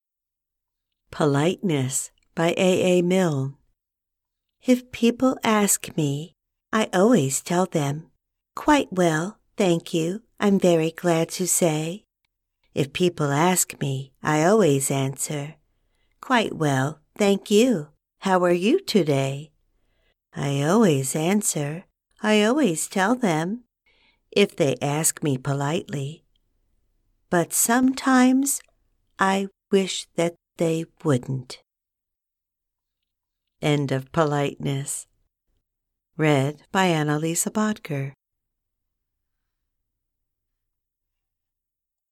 Poem